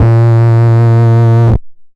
Wobble Bass Live (JW3).wav